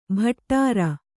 ♪ bhaṭṭāra